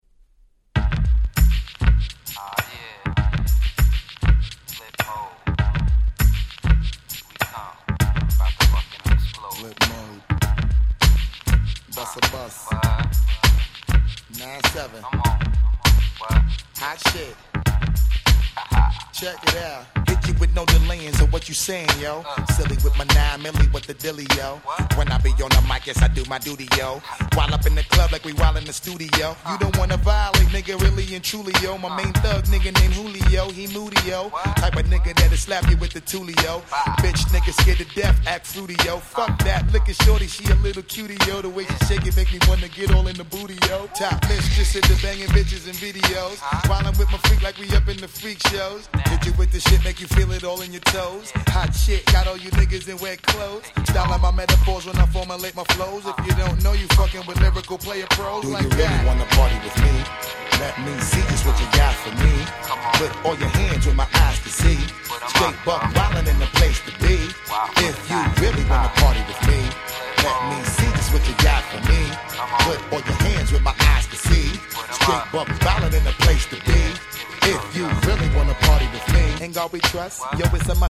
97' Super Hit Hip Hop !!
97年を代表するフロアヒット！！